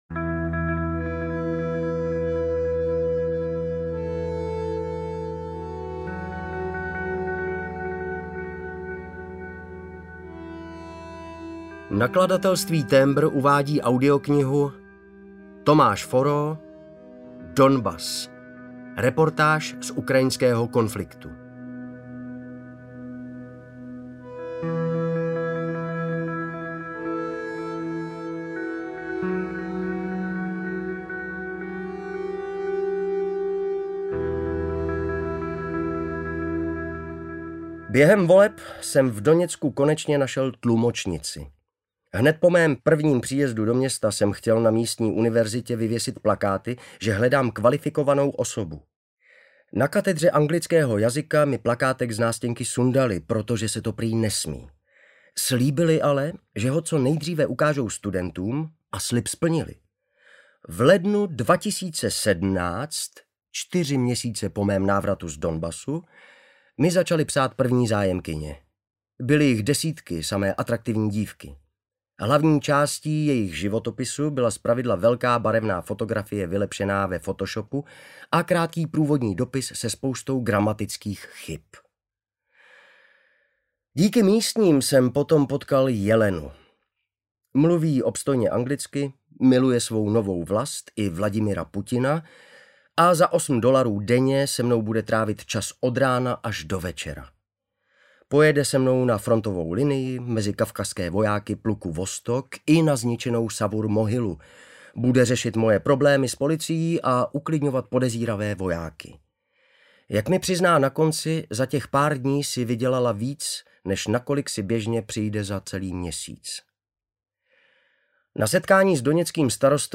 Donbas: Reportáž z ukrajinského konfliktu audiokniha
Ukázka z knihy